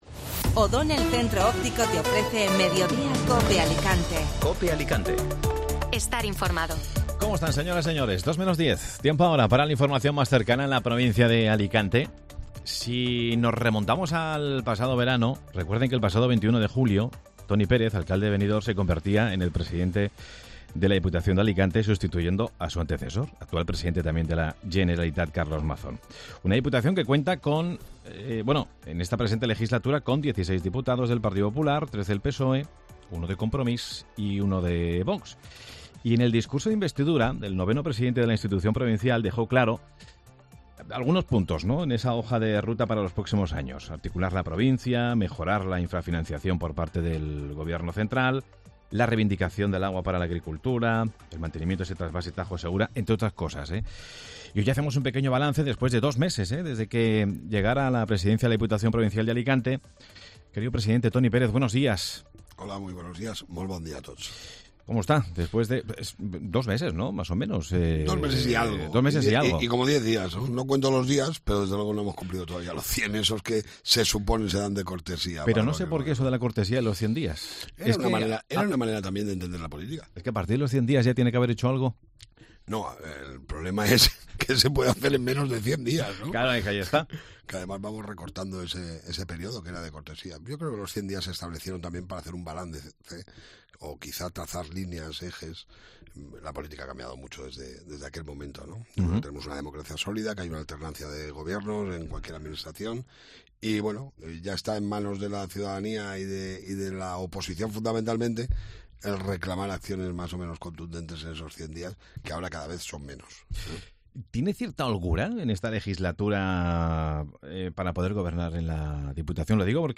Escucha la entrevista al presidente de la Diputación en Mediodía COPE Alicante
Entrevista a Toni Pérez, presidente de la Diputación de Alicante